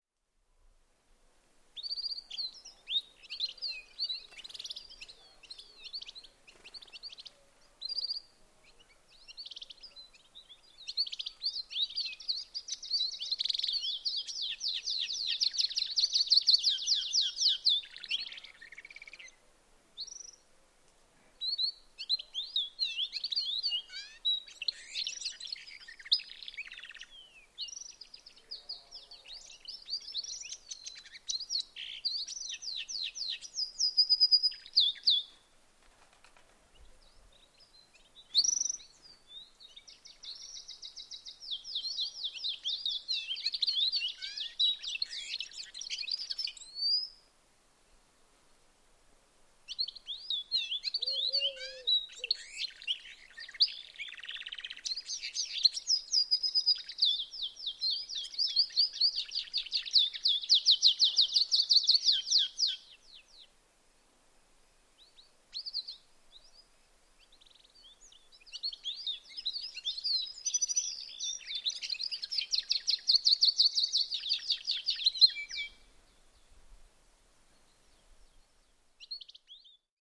На этой странице вы можете слушать и скачивать звуки канарейки – удивительно мелодичные и чистые трели, которые украсят ваш день.
Голос канарейки записанный в Испании